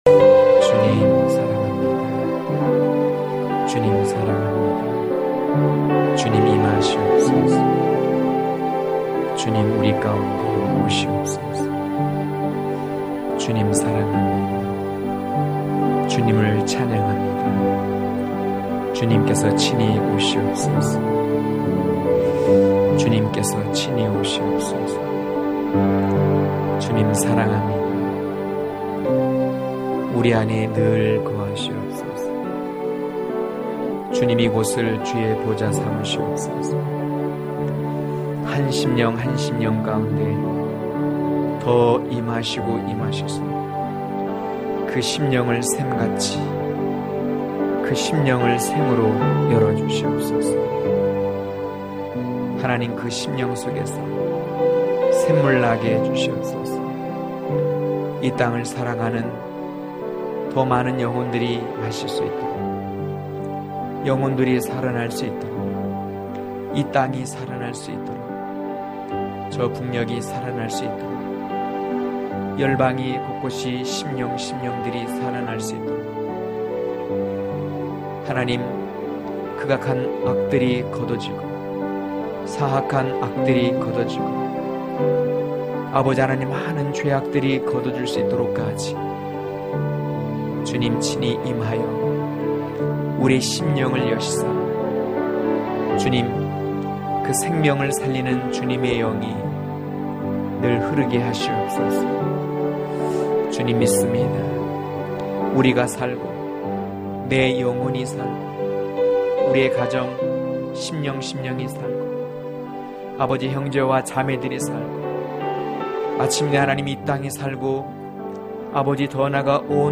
강해설교 - 01.사랑의 노래, 아가의 동산(아1장1-4절)